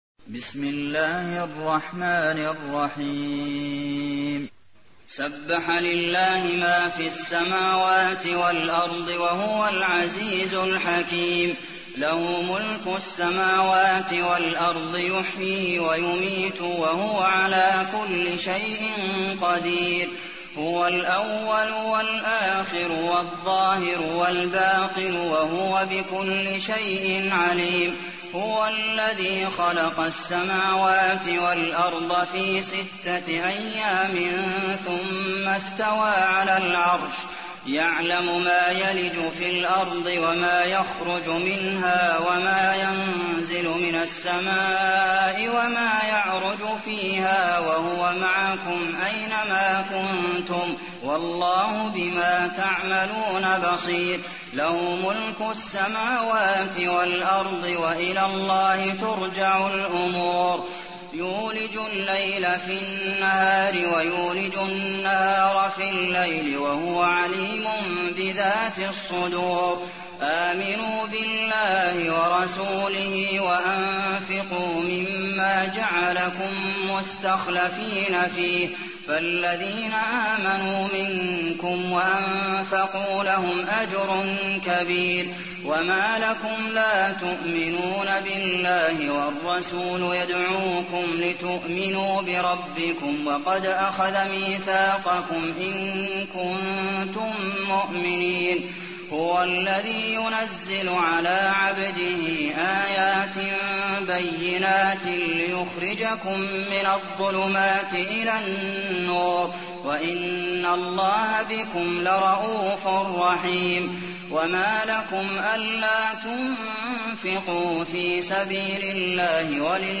تحميل : 57. سورة الحديد / القارئ عبد المحسن قاسم / القرآن الكريم / موقع يا حسين